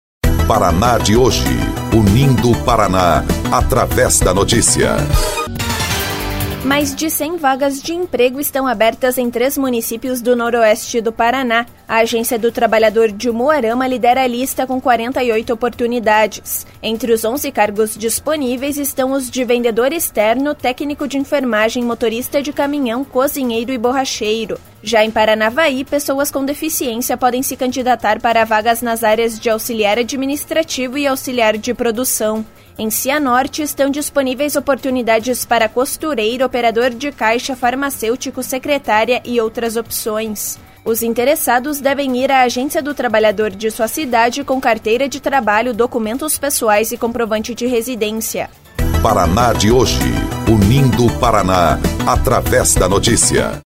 BOLETIM - Agências do Trabalhador do noroeste oferecem mais de 100 vagas de emprego